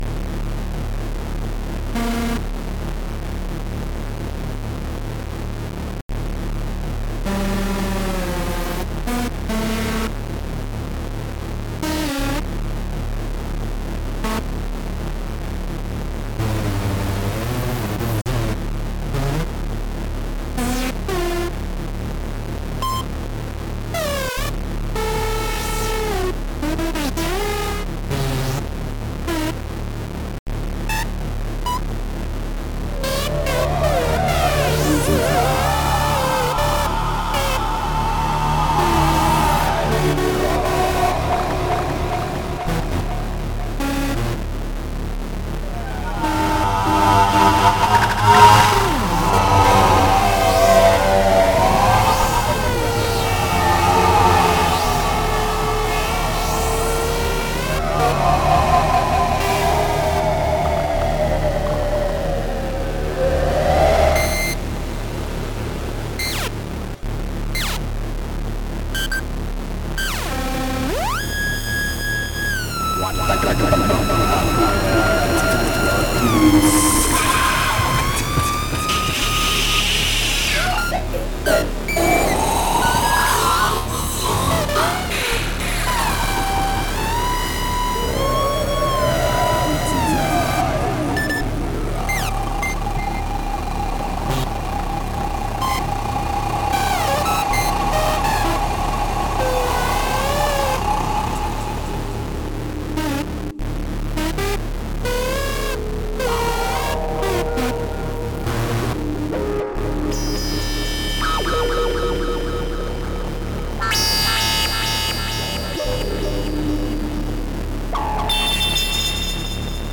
Borborythmes et incantations ?
Puisqu'on est dans les grognements...